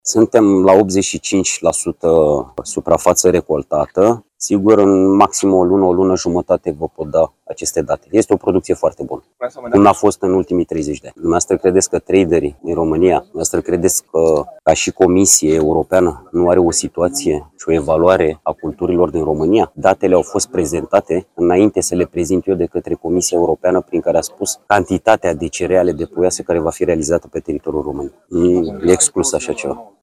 25iul-17-CORESP-AR-voce-Florin-Barbu-productie-record-de-grau-.mp3